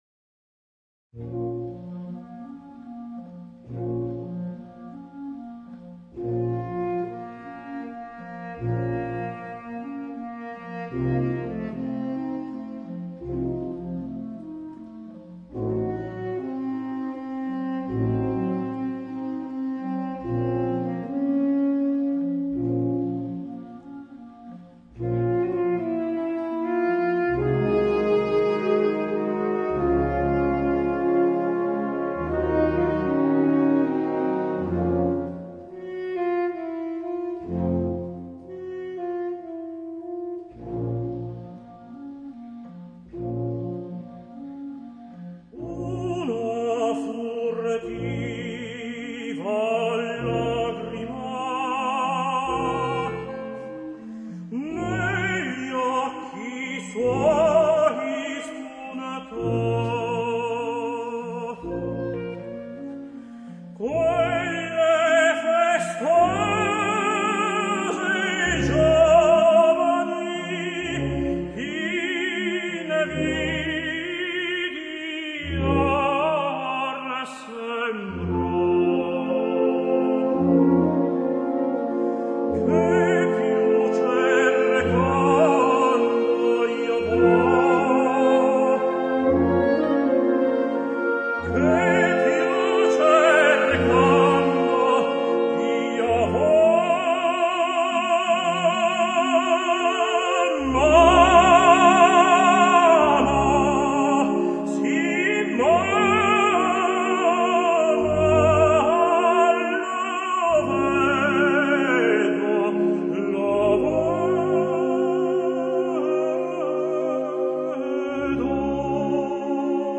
Per tenore e banda
La celebre romanza
per tenore o eufonio e banda.